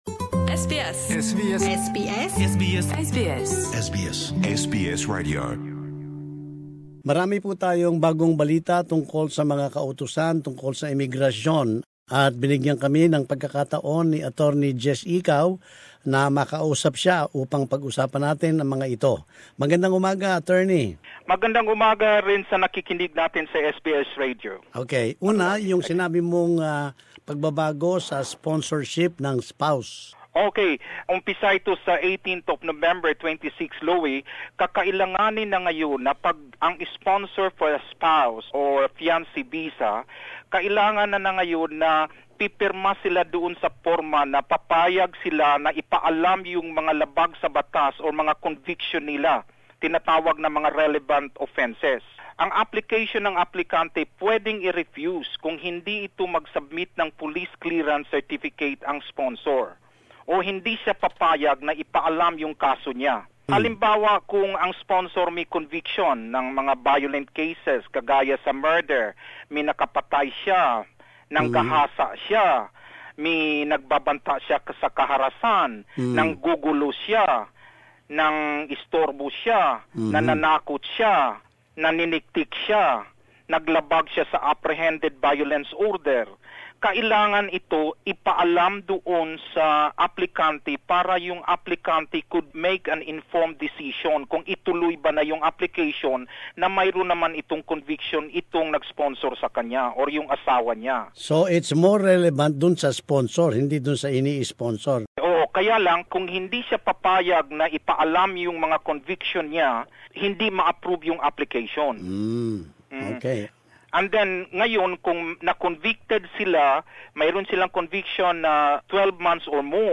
We spoke to an expert lawyer, who has wide knowledge of immigration matters, to explain the contents of the new rules.